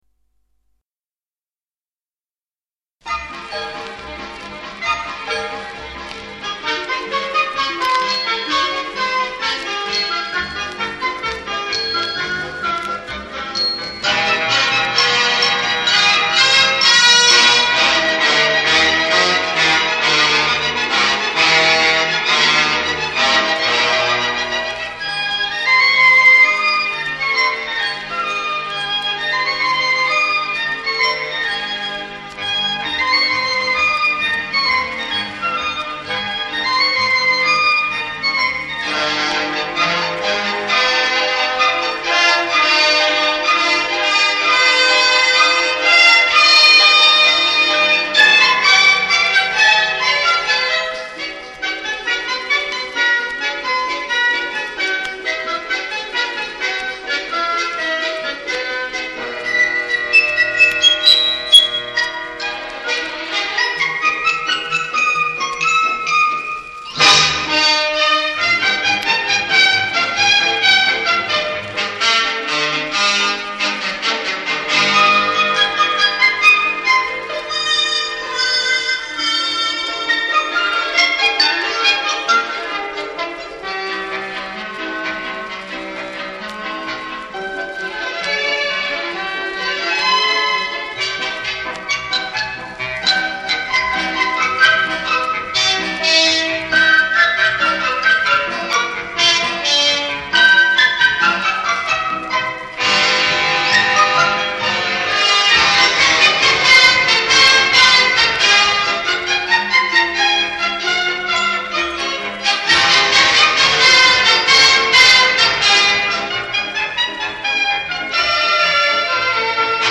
Music from the Soundtrack of